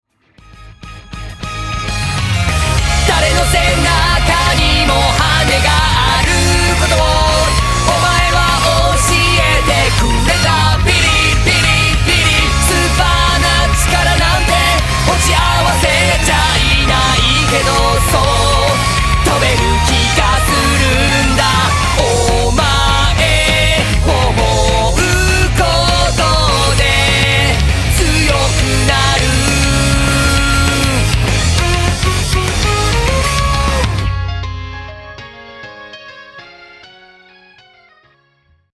アイドルソング